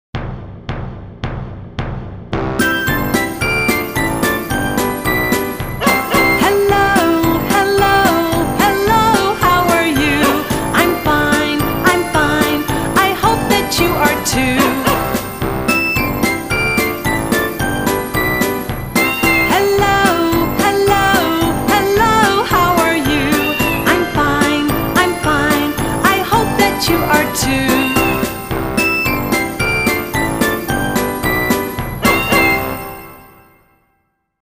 トピックにマッチした歌や英語圏でポピュラーな英語の歌をお届けします。